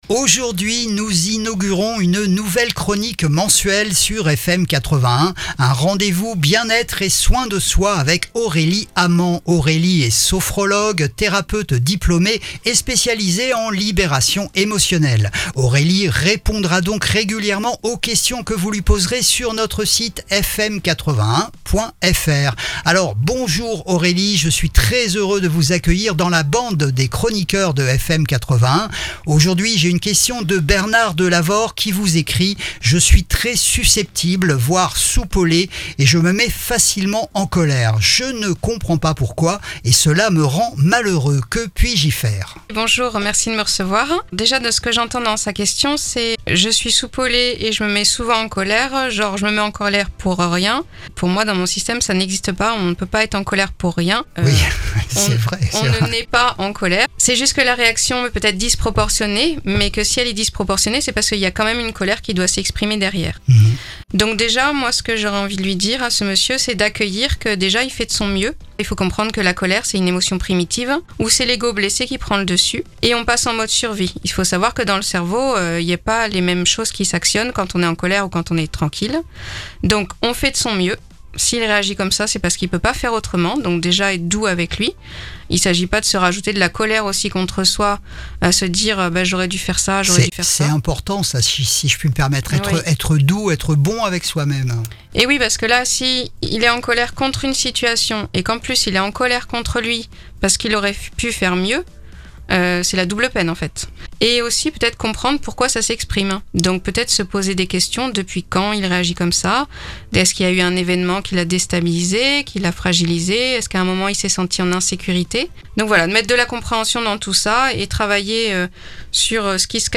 Une nouvelle chronique sur FM81 !